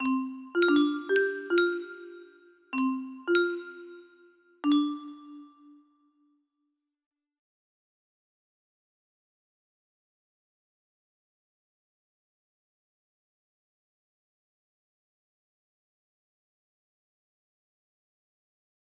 Unknown Artist - Untitled music box melody
Wow! It seems like this melody can be played offline on a 15 note paper strip music box!